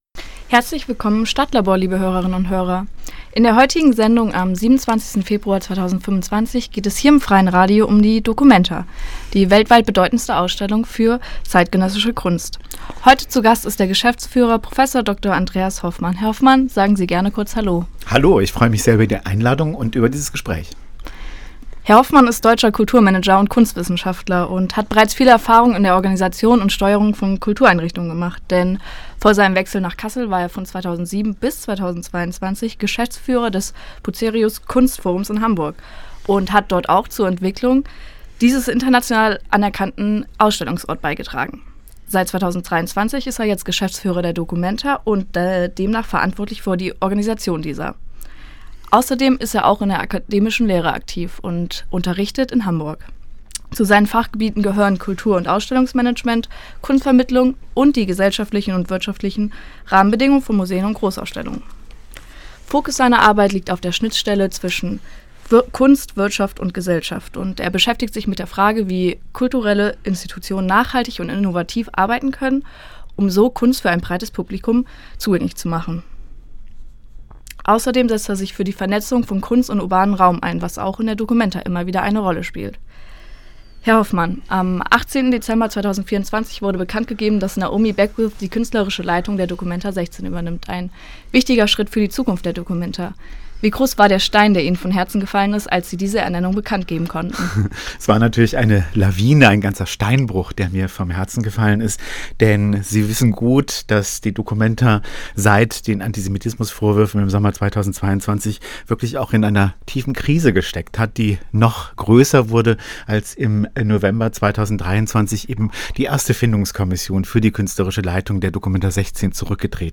Und es gibt einen Ausblick auf die Veranstaltungen zu „70 Jahre documenta“ in diesem Jahr. Das StadtLabor wurde am 27.2.2025 im Freien Radio Kassel gesendet. Die Musiktitel sind im Podcast nicht enthalten.